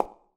darkwatch/client/public/dice/sounds/surfaces/surface_felt3.mp3 at ed45a84e5a4b93e5d2caca0f8cb36948d0d6e270
surface_felt3.mp3